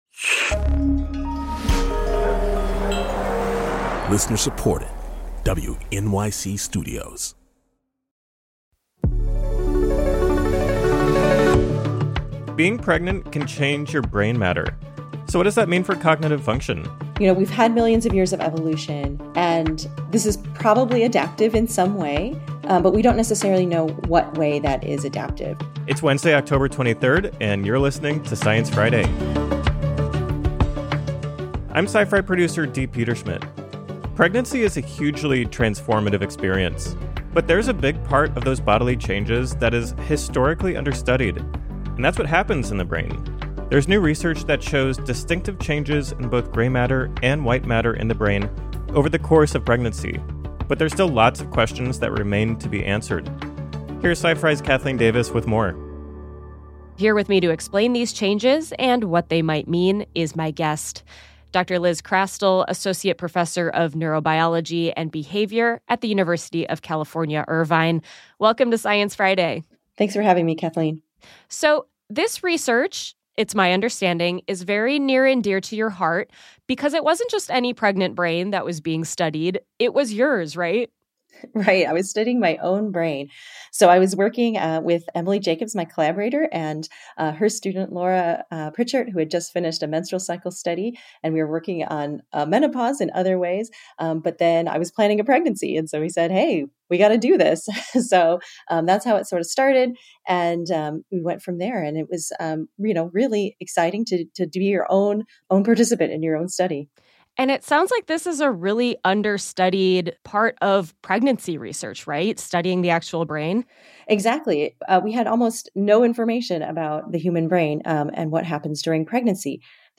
Joining guest host